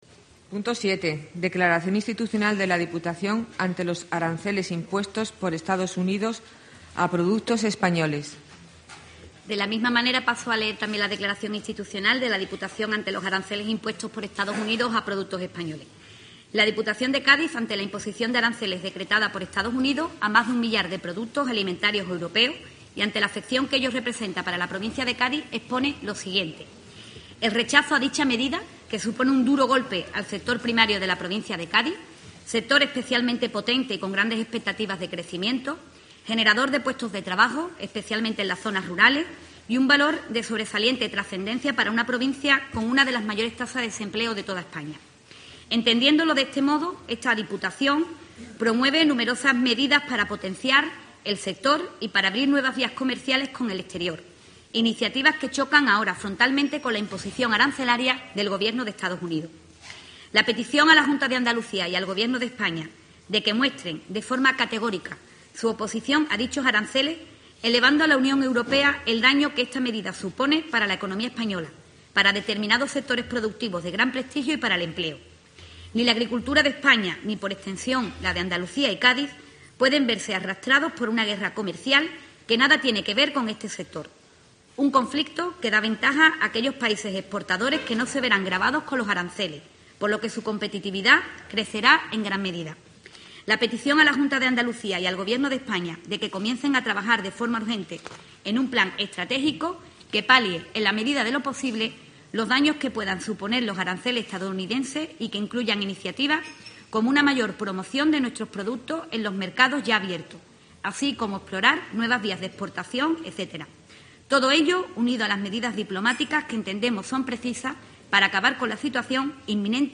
Irene García, presidenta de Diputación, sobre los aranceles